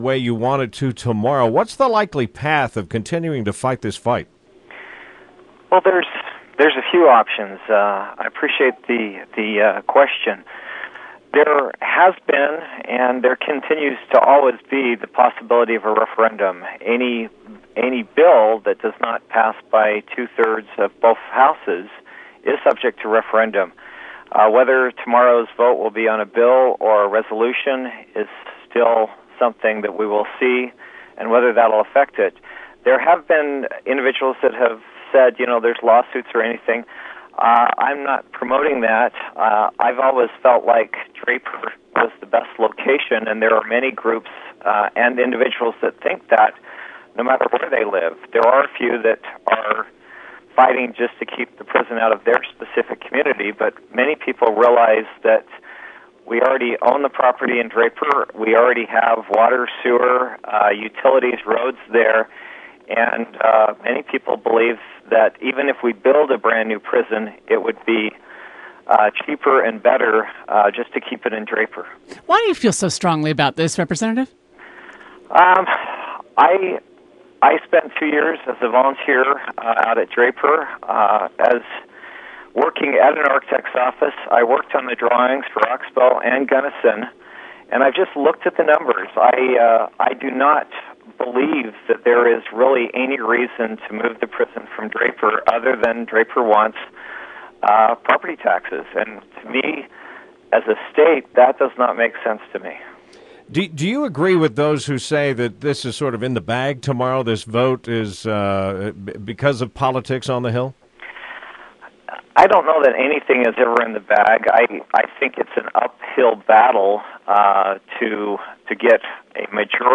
Interview: WVC Representative Fred Cox